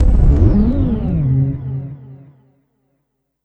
35SFX 01  -R.wav